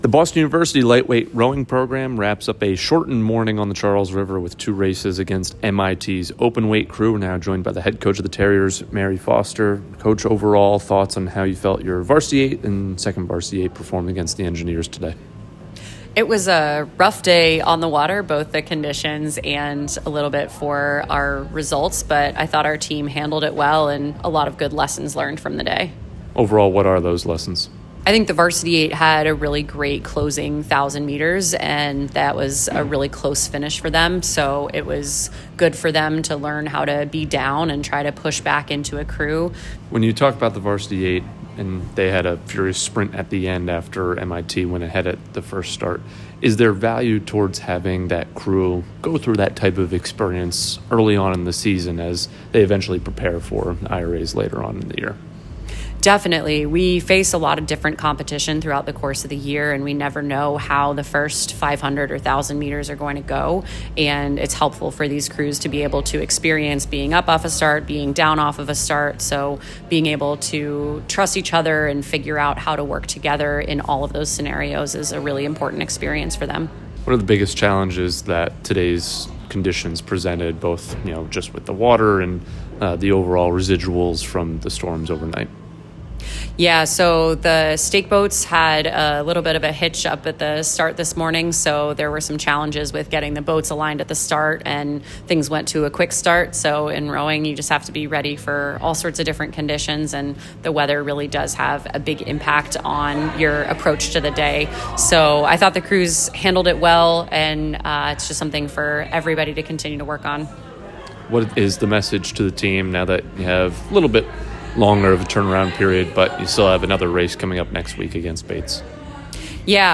LTROW_MIT_Open_Postrace.mp3